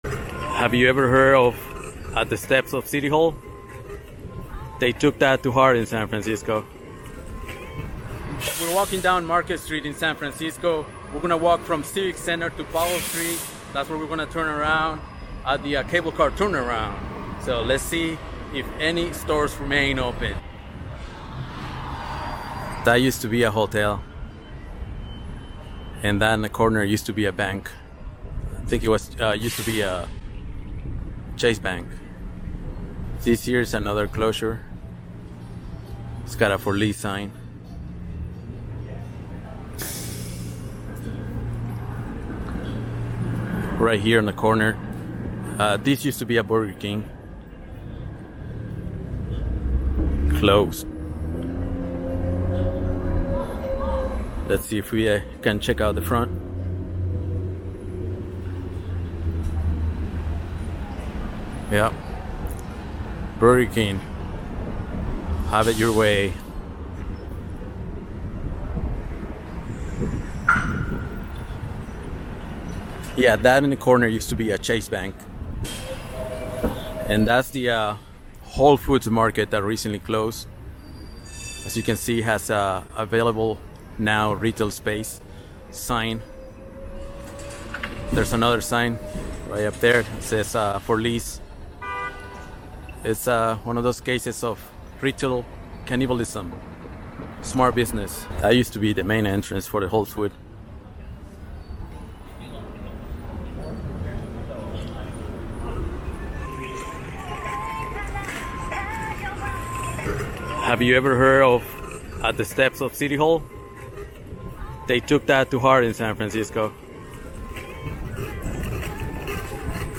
San Francisco Walking Downtown every store is CLOSED on market st